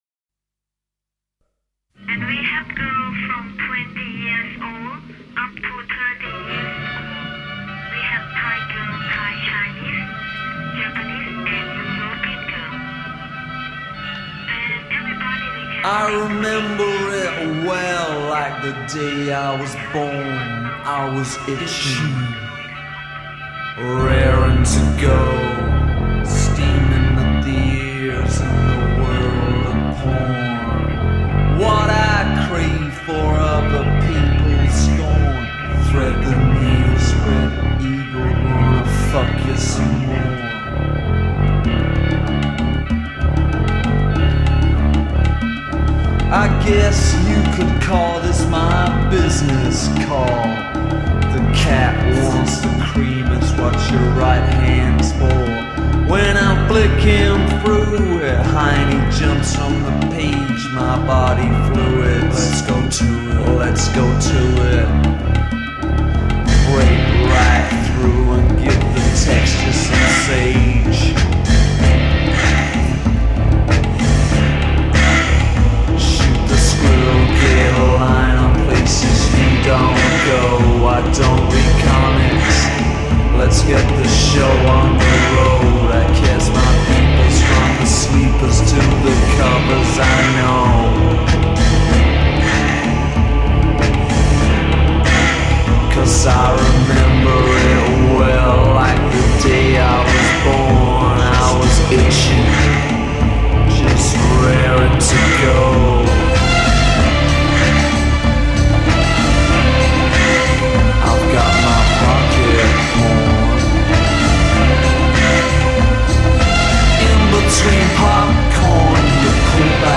Pop/New-Wave underground 80′s